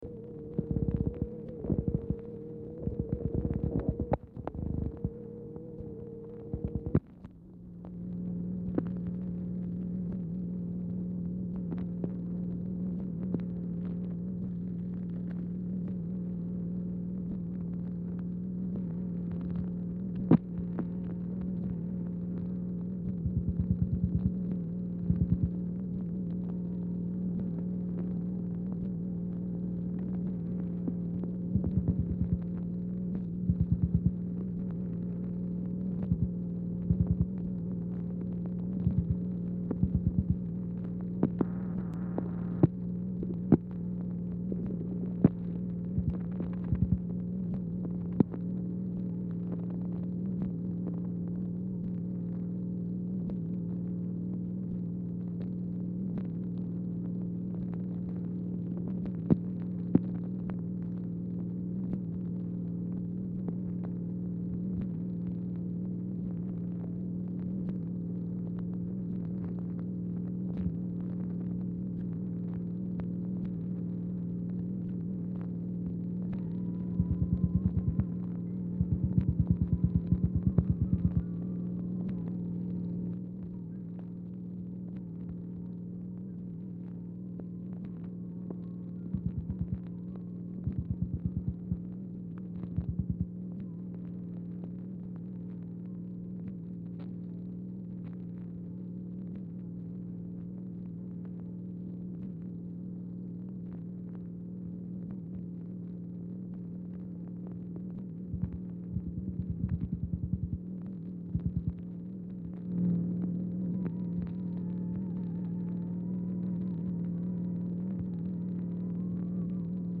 Telephone conversation # 311, sound recording, MACHINE NOISE, 12/6/1963, time unknown | Discover LBJ
Telephone conversation
Format Dictation belt